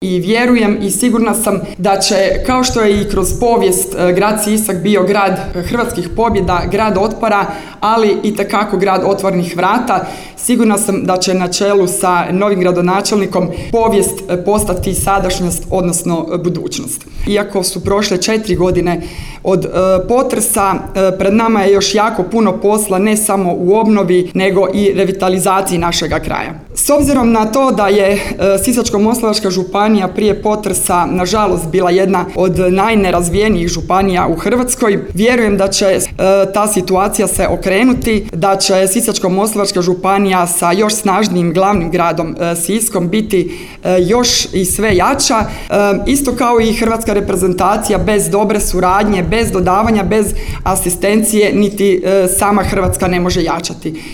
Tim povodom u srijedu, 4. lipnja 2025. godine, održana je svečana sjednica Gradskog vijeća Grada Siska.
Čestitke je u ime predsjednika Hrvatskog sabora Gordana Jandrokovića u ovoj prigodi uputila njegova izaslanica, saborska zastupnica i gradonačelnica Petrinje Magdalena Komes